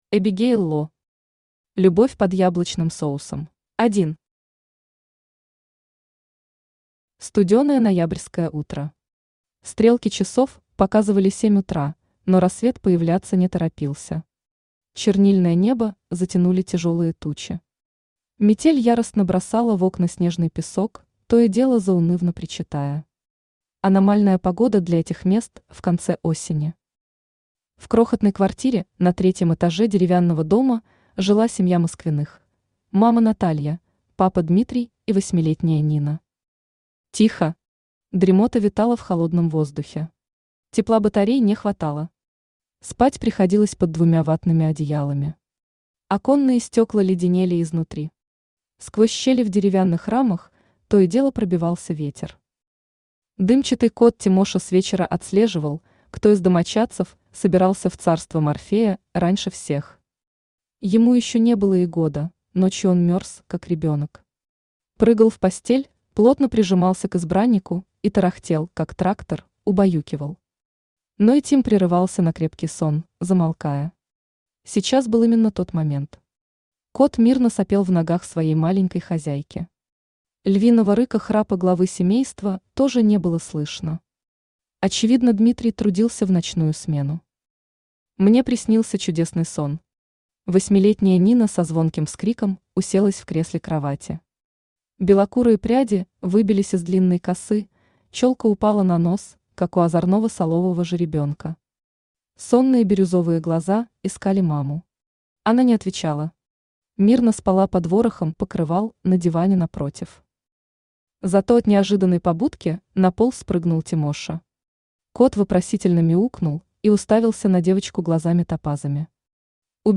Аудиокнига Любовь под яблочным соусом | Библиотека аудиокниг
Aудиокнига Любовь под яблочным соусом Автор Эбигейл Ло Читает аудиокнигу Авточтец ЛитРес.